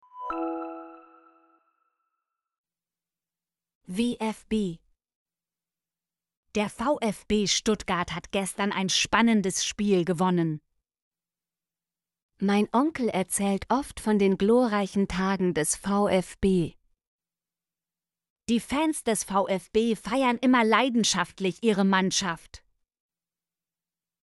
vfb - Example Sentences & Pronunciation, German Frequency List